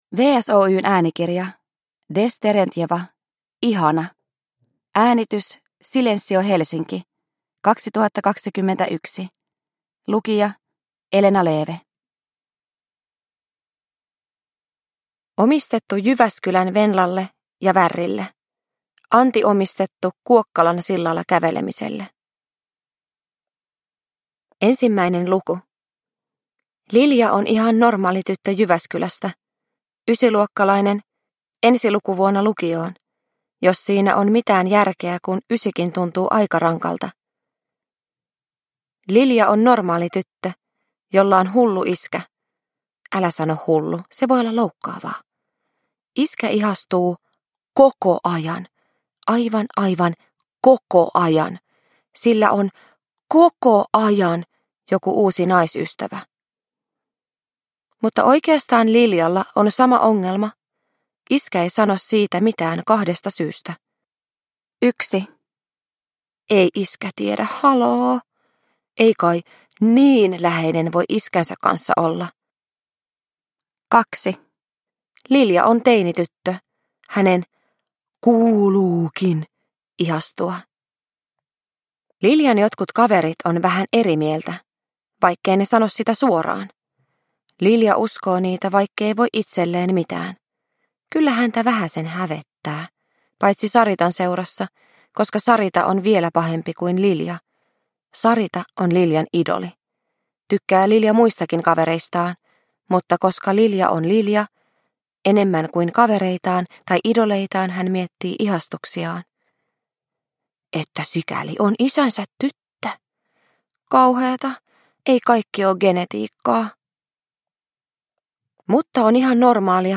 Ihana – Ljudbok – Laddas ner
Uppläsare: Elena Leeve